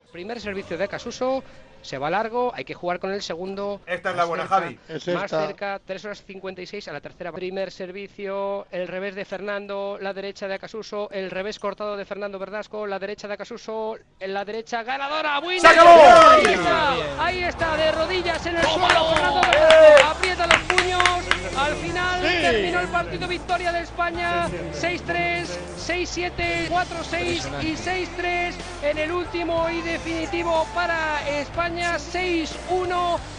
Transmissió de la final de la Copa Davis de Tennis, des del Polideportivo Islas Malvinas de Mar del Plata (Argentina).
Esportiu